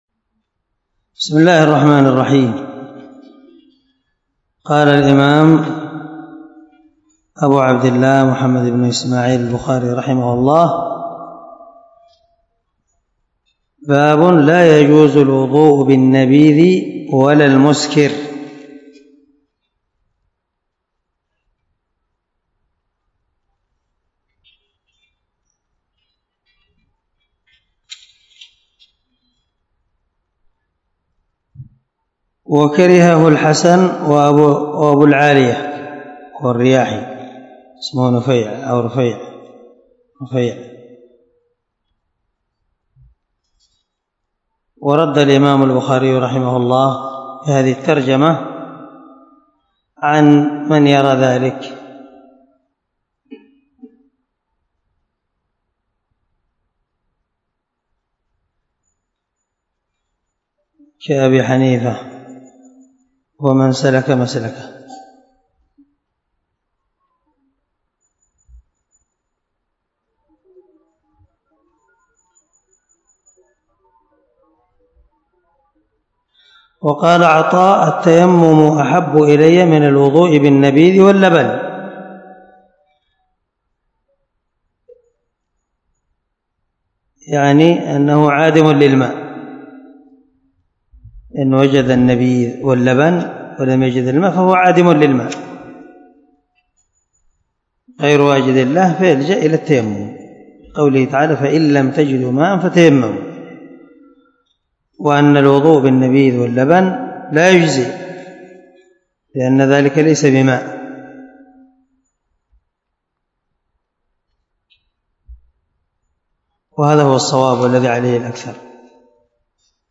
177الدرس 53 من شرح كتاب الوضوء حديث رقم ( 206 ) من صحيح البخاري
دار الحديث- المَحاوِلة- الصبيحة.